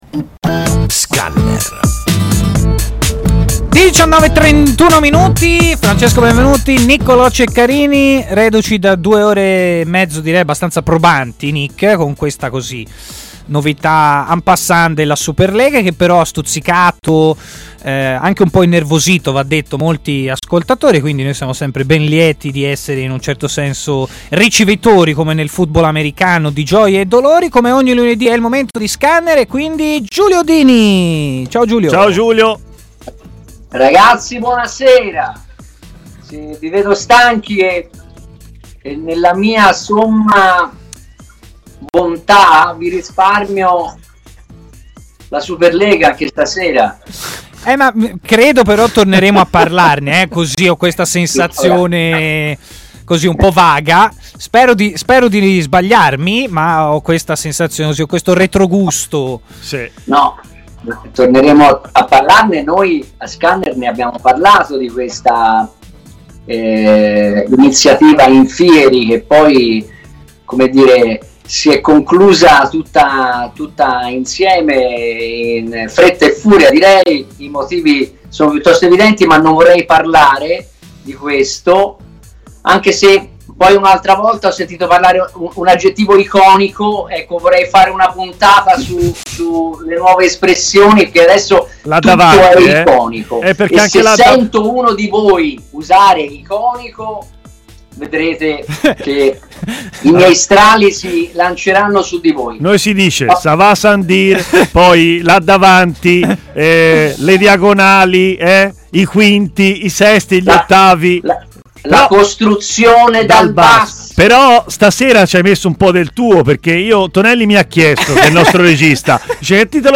L'intervento integrale nel podcast!